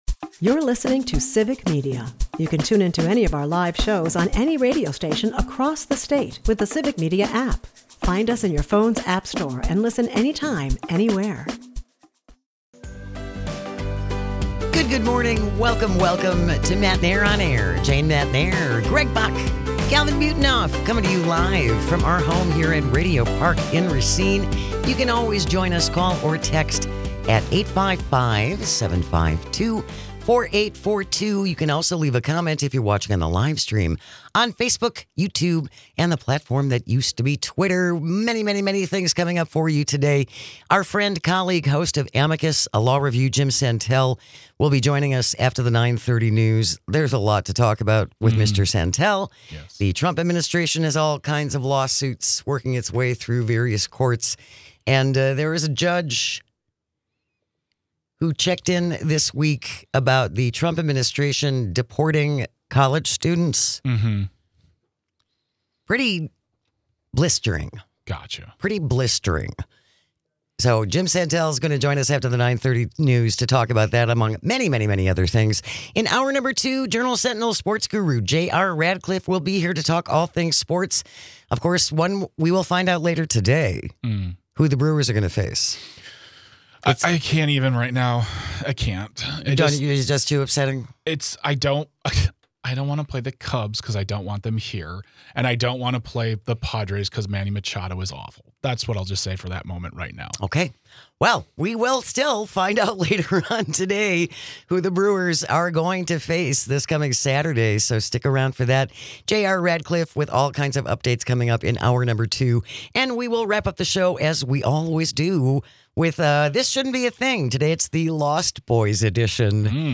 Then, Jim Santelle is the host of Amicus: A Law Review and he joins us to talk about all of the major stories coming out of the world of law including a judge who spoke up against the president and is being threatened and the upcoming US Supreme Court docket (and a fascinating comment from one of their Justices ).